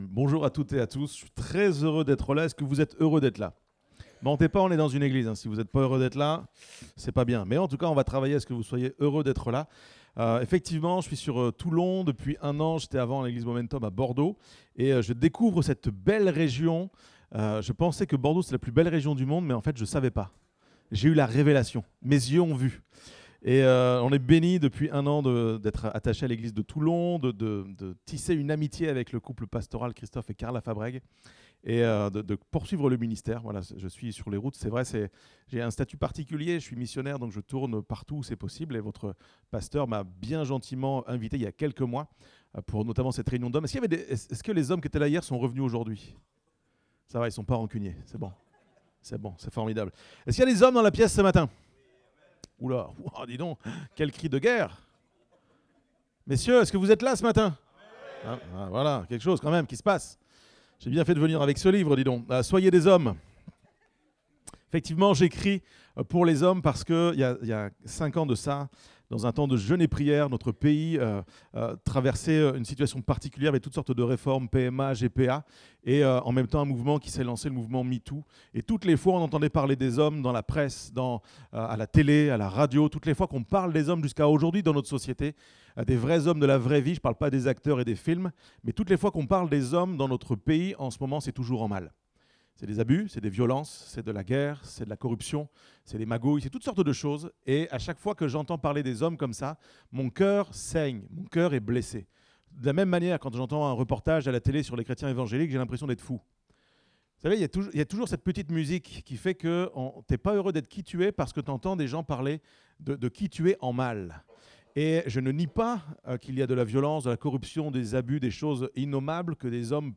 Date : 22 septembre 2024 (Culte Dominical)